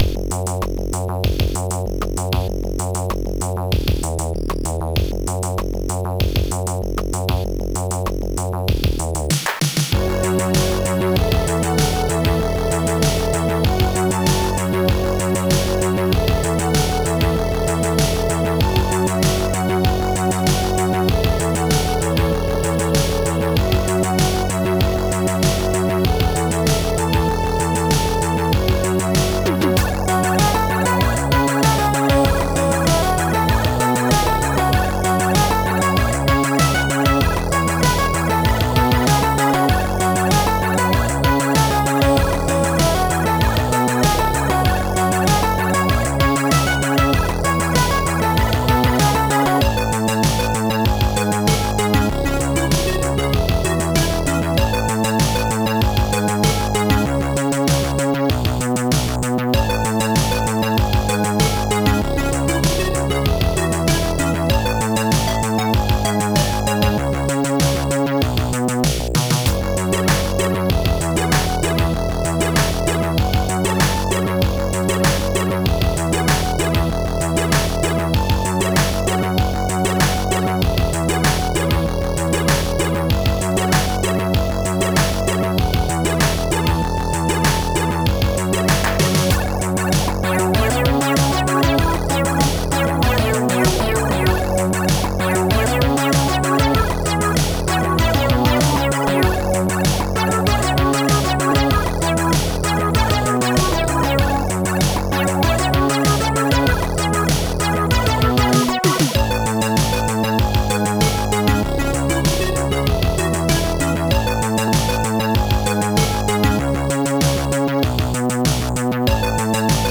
Song Platform: PlatformAMIGA-custom
Made with The Ultimate Soundtracker in 2025.
Only 15 samples
Only two effects, portamento and arpeggio.
MP3 conversion from Amiga 1200: